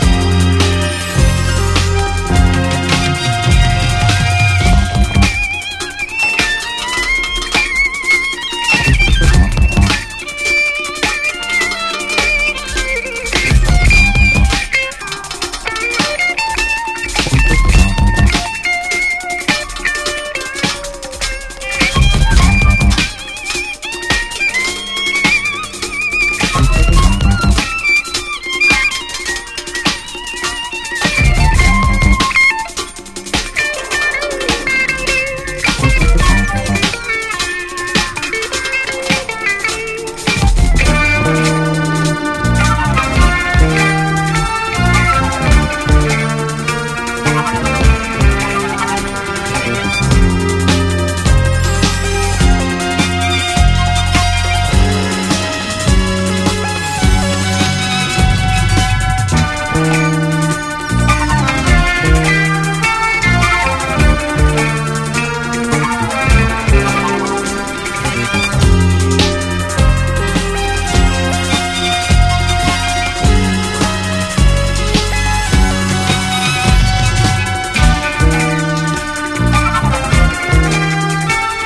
EASY LISTENING / EASY LISTENING / SPACE / DISCO
ファンキーな宇宙SFサントラ・カヴァー・アルバム！
ジャケはB級ながらも、音はイージー畑の巨匠ならではゴージャスさがソソります！